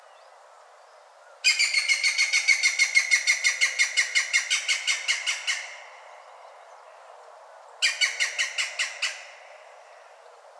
Peto verde
Picus viridis
Canto
O Peto verde é coñecido polo seu chamado distintivo, que soa como unha risa resonante e repetitiva, audible a grandes distancias no seu contorno natural.